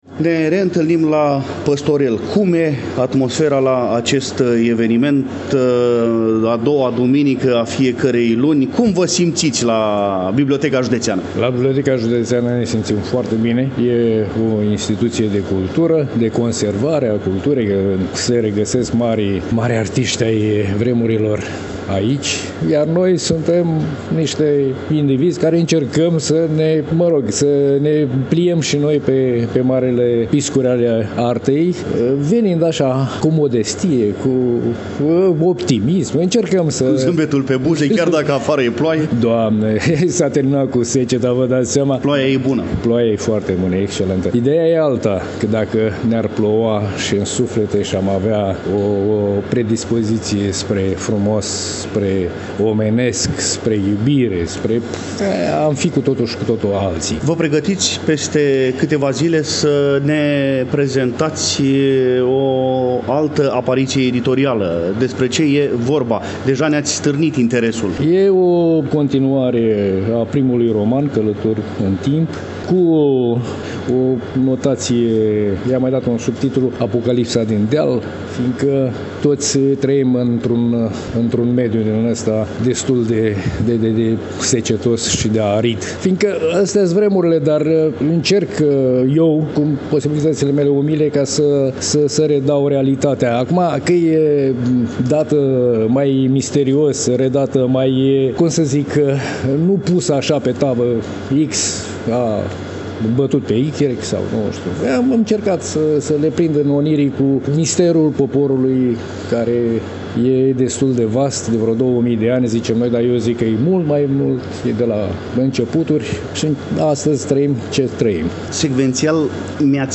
cu emoție în glas și suflet